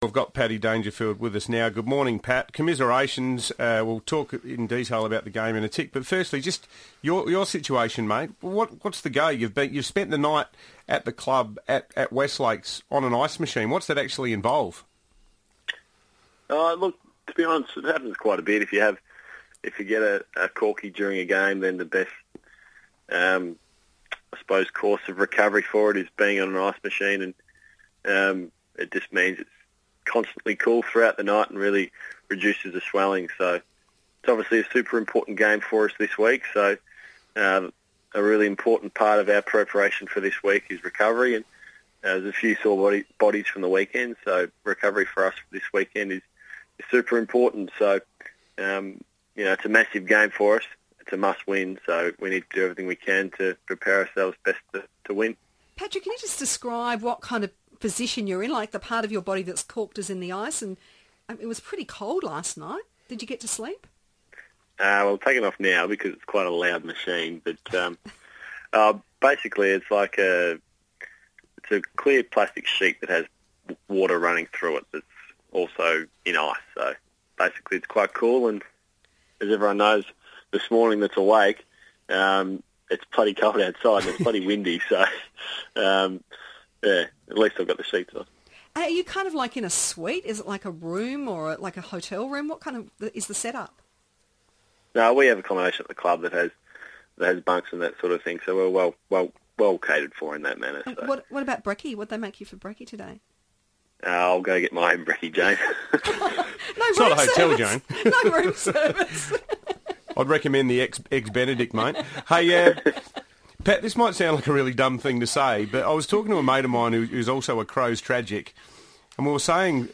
Patrick Dangerfield spoke to the FIVEaa breakfast team following Adelaide's disappointing loss to the Bombers.